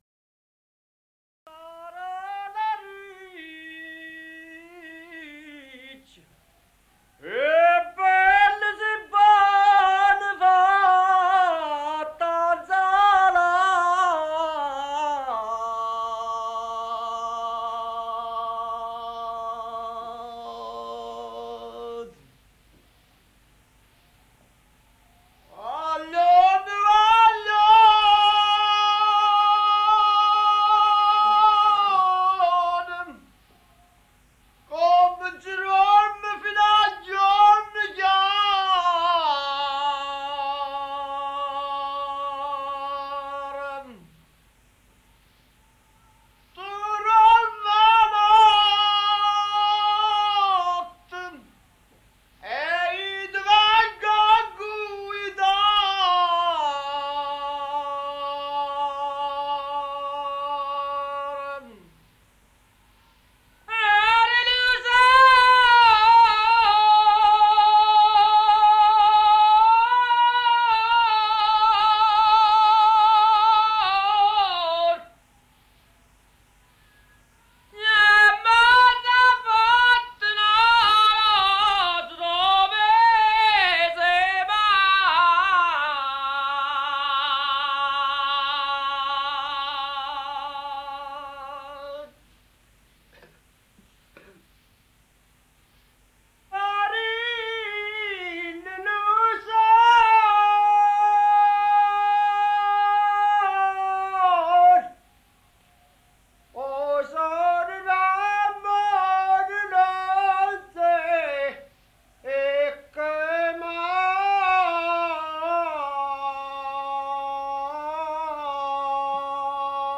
5 - A group of porters in the village of Positano - Porters Song - Scarola Riccia, Sung by a Mulateer.mp3